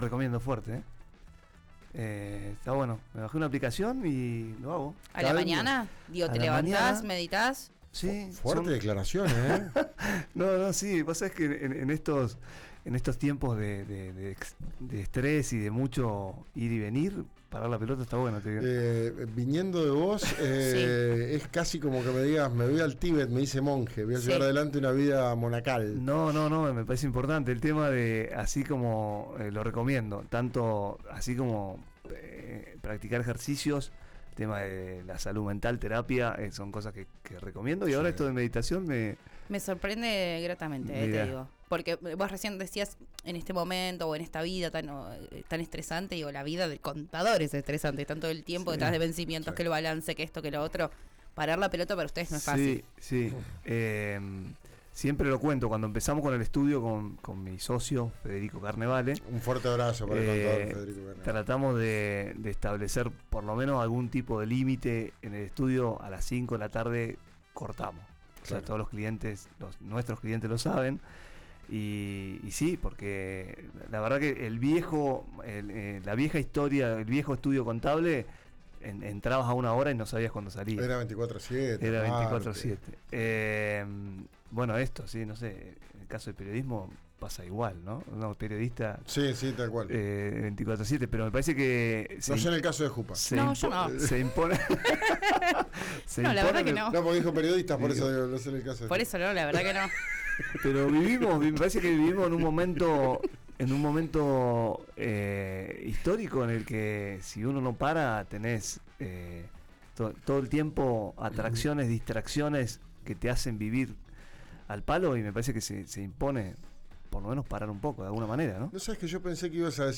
en diálogo con 'Ya es tiempo'.
Vouchers educativos y reforma laboral: la explicación de un contador experto